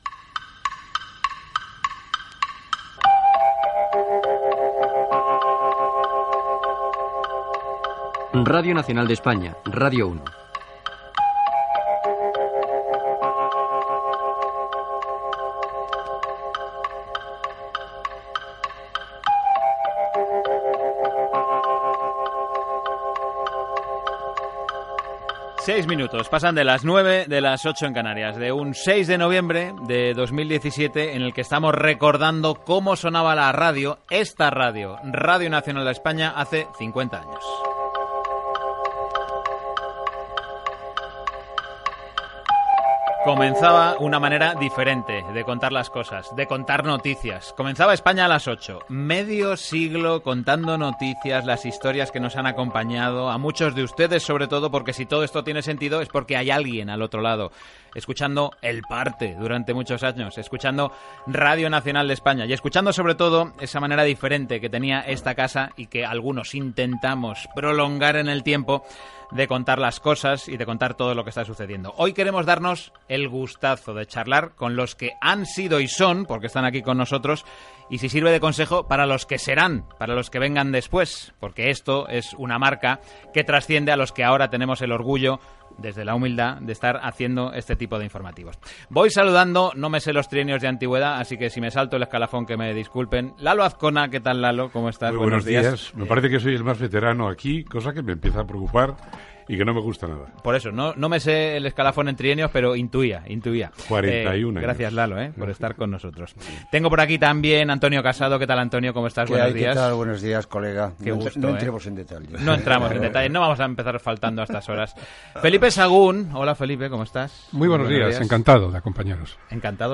Roda de corresponsals des de Brusel·les, Roma, París, Berlín i Pequín Gènere radiofònic Info-entreteniment